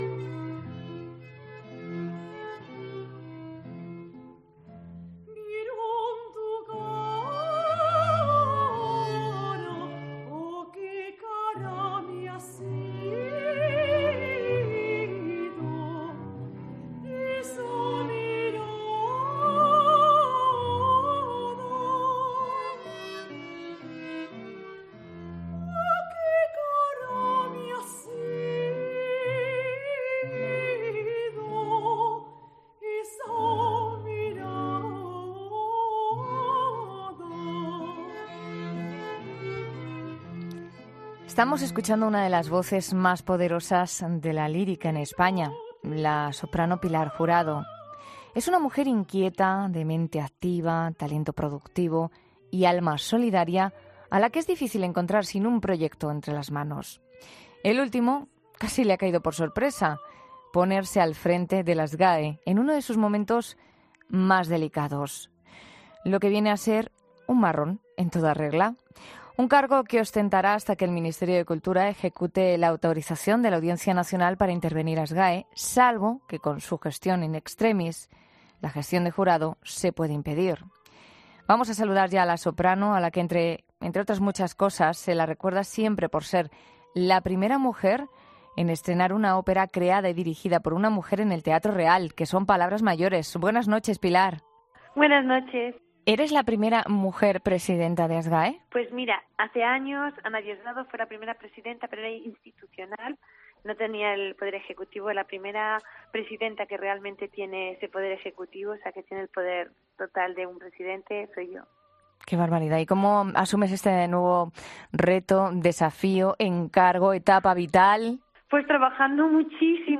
Hablamos con la soprano, a la que, entre muchas cosas la recordamos siempre por ser la primera mujer en estrenar una ópera creada y dirigida por una mujer en el Teatro Real.